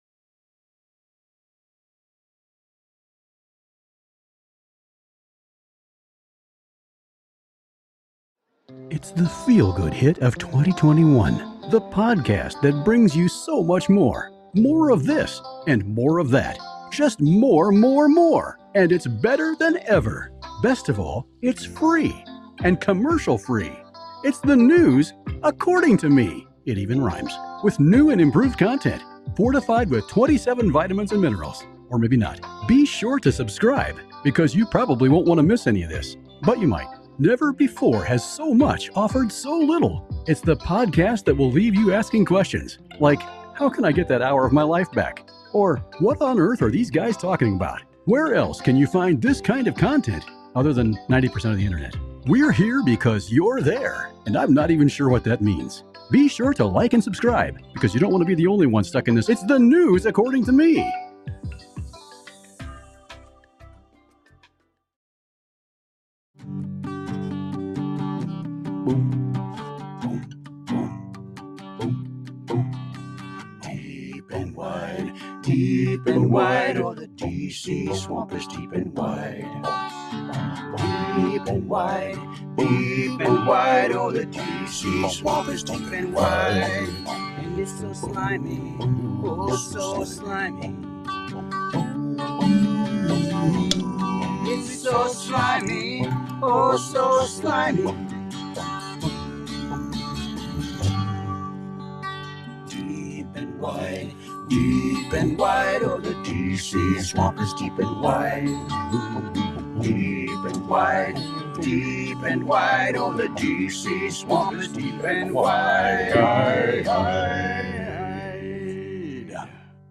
Wednesday LIVE show! Just a mish mash of things to talk about.